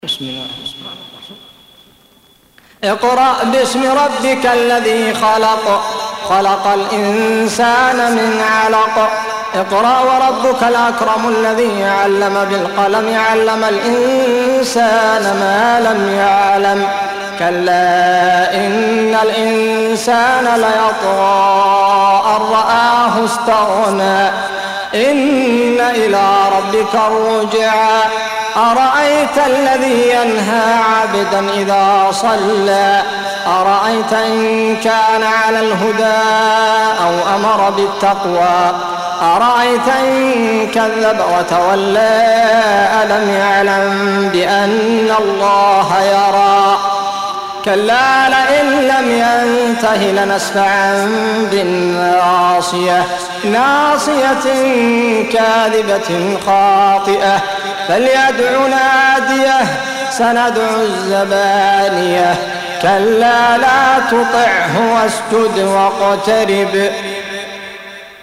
Surah Sequence تتابع السورة Download Surah حمّل السورة Reciting Murattalah Audio for 96. Surah Al-'Alaq سورة العلق N.B *Surah Includes Al-Basmalah Reciters Sequents تتابع التلاوات Reciters Repeats تكرار التلاوات